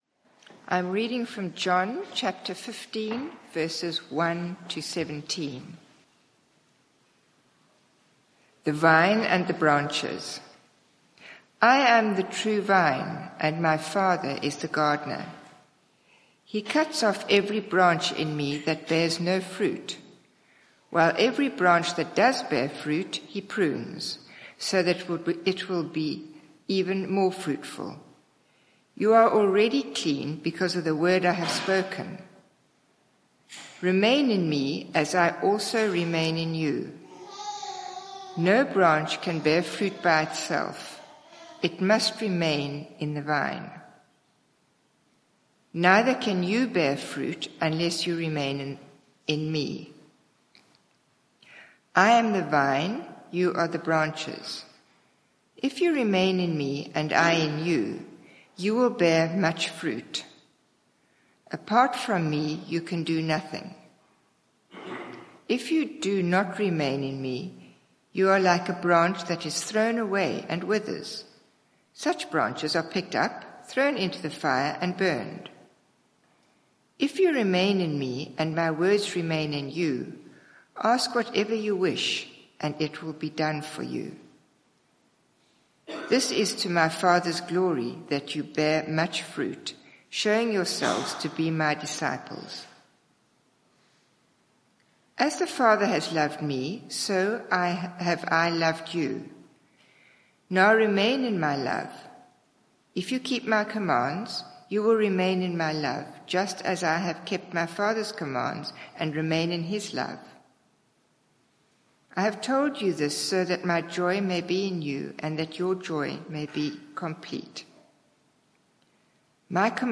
Media for 9:15am Service on Sun 01st Sep 2024 09:15 Speaker
I am the true vine Sermon (audio)